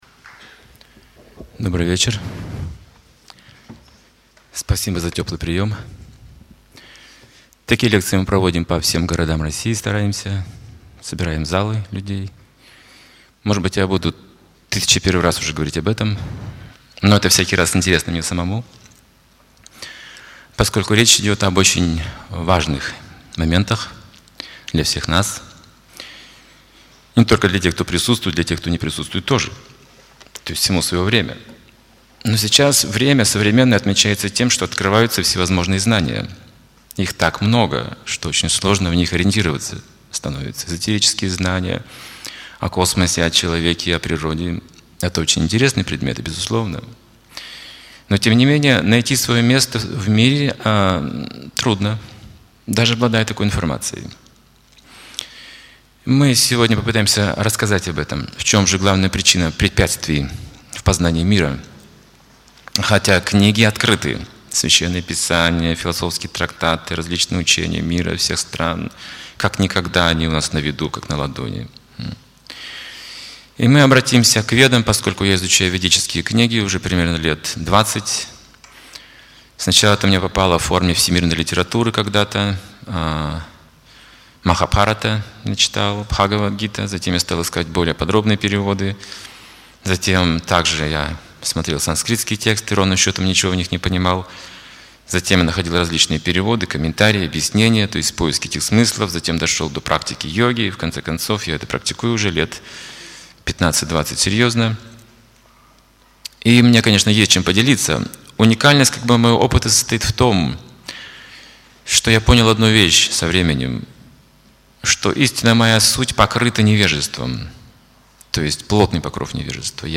Цикл лекций «Познание себя» ( Краснодар, 2005 г.)